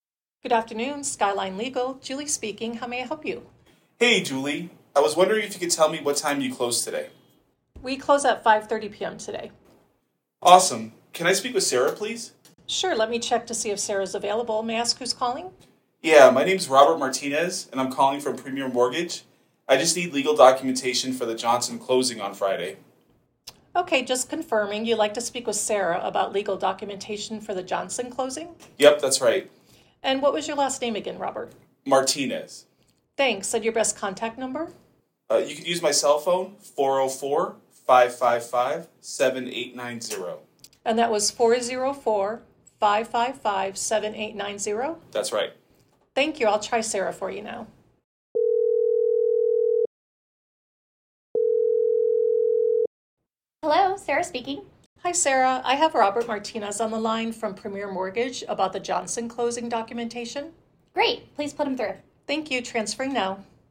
business-telephone-answering-service-sample-call-ReceptionistPlus.mp3